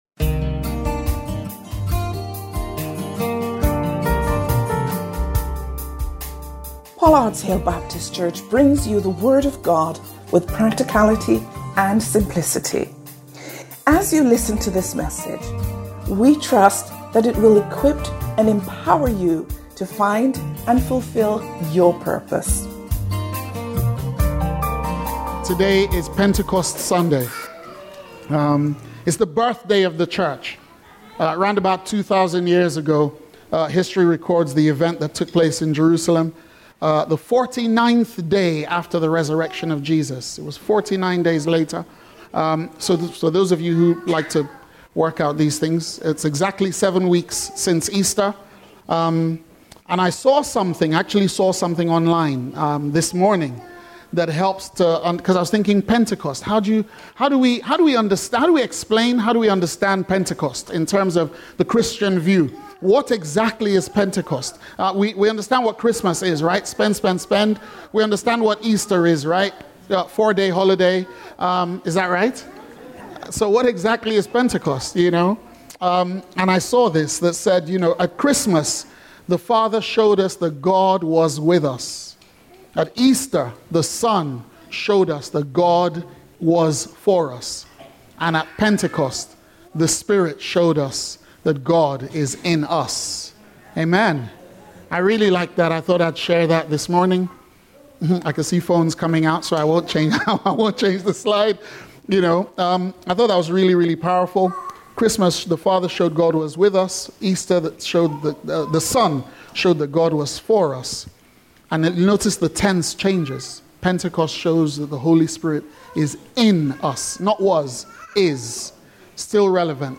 Watch this service and more on our YouTube channel – CLICK HERE